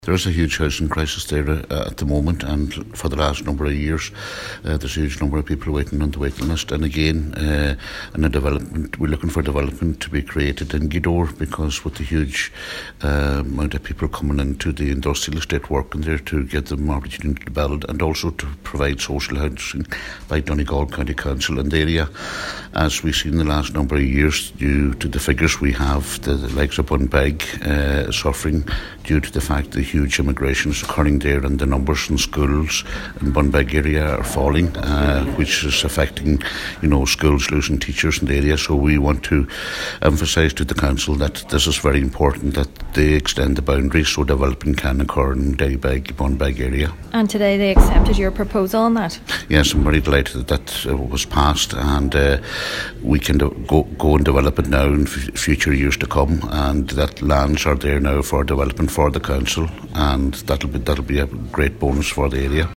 Councillor John Sheamuis O’Fearraigh welcomed this decision and says given the housing problem in Ghaoth Dobhair it is important that boundaries are extended to allow for development: